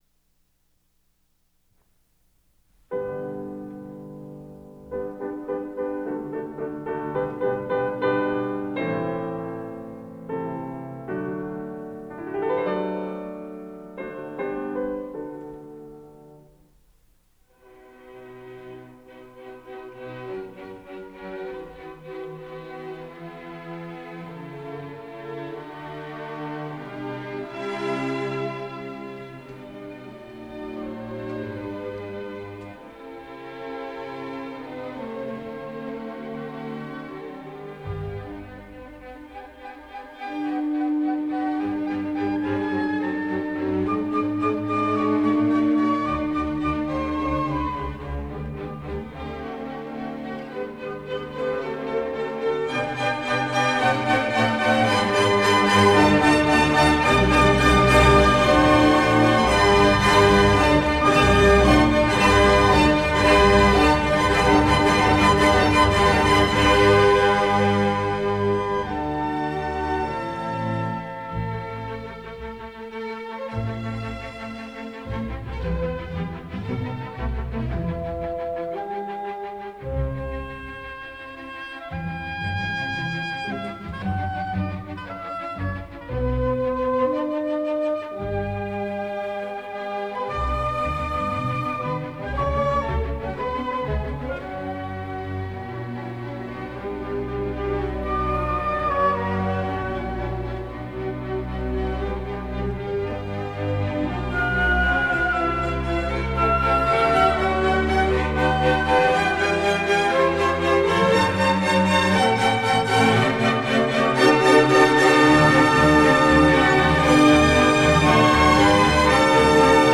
piano
16-22 Apr 1958 Sofiensaal, Vienna
Transferred from a 4-track tape